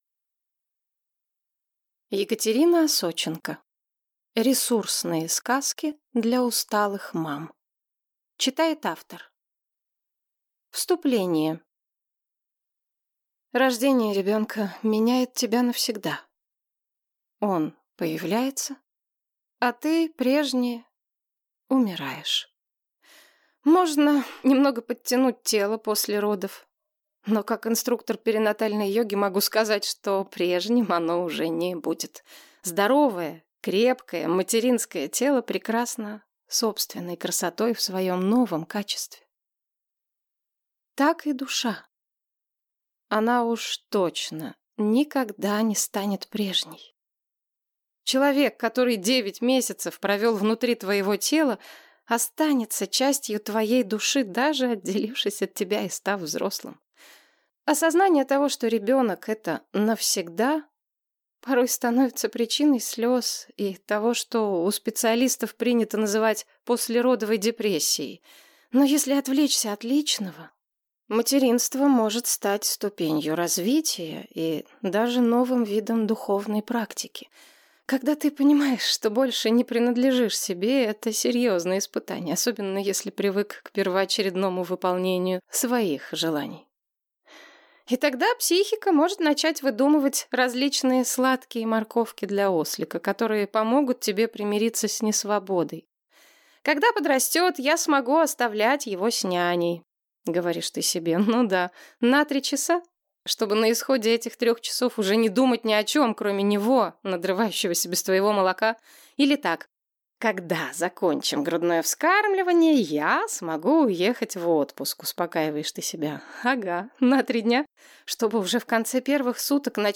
Аудиокнига Ресурсные сказки для усталых мам | Библиотека аудиокниг